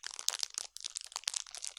Large Fire Crackle.wav